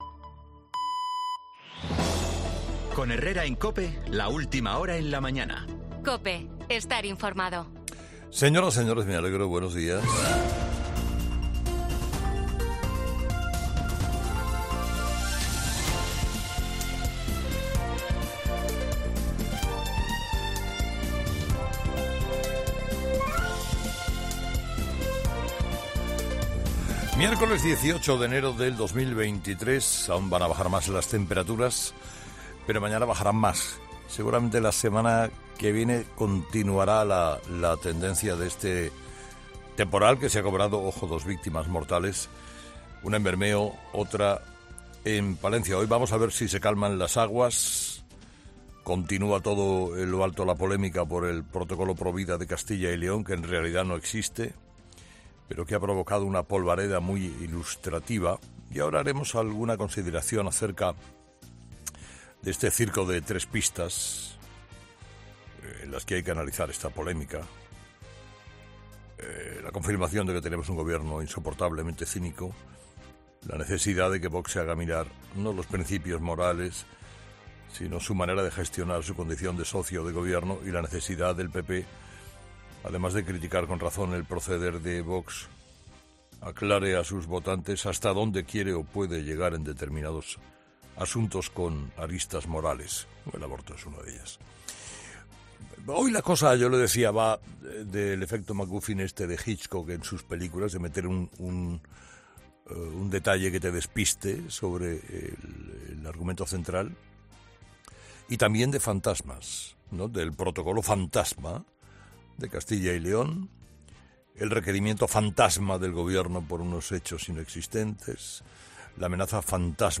Carlos Herrera analiza por qué la estrategia de Vox puede beneficiar a Sánchez y al PSOE